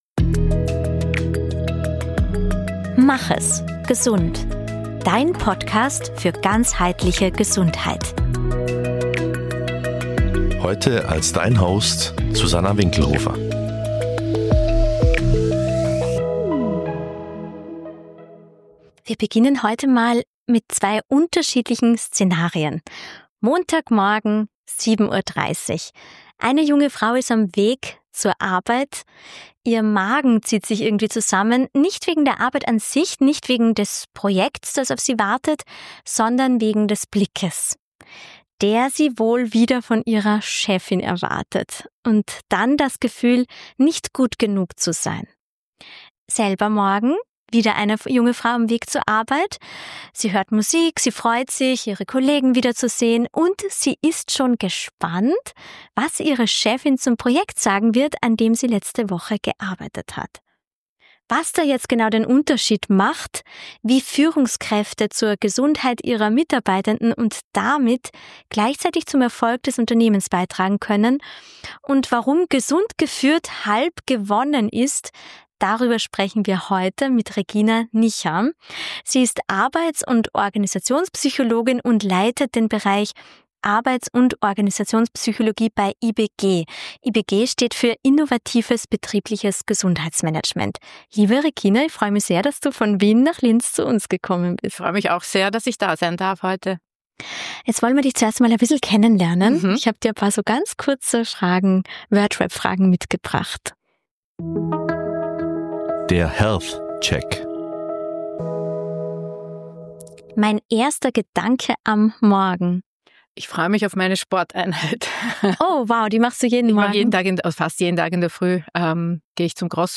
Ein Gespräch über Wertschätzung, die ankommt. Über Grenzen zwischen Fürsorge und Überforderung.